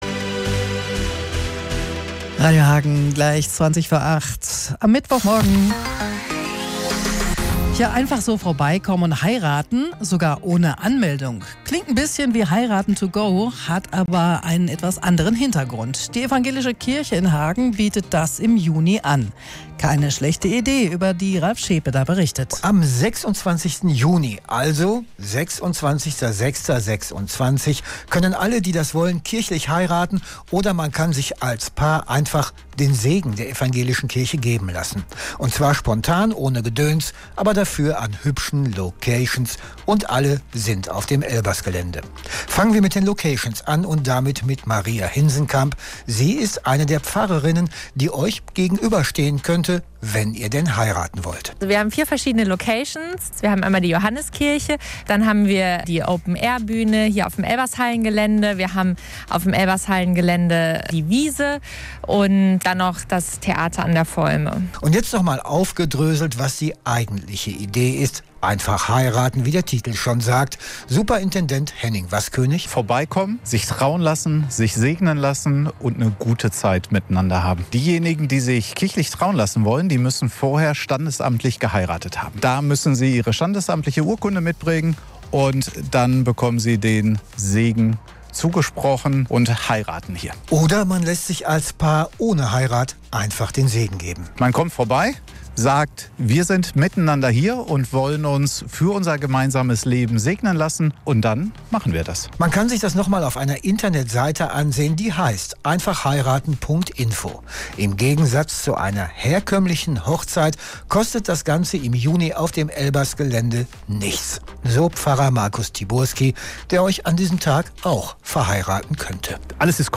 Zu dem Thema haben wir auch einen Beitrag in unserem Morgenprogramm gesendet - der ist hier: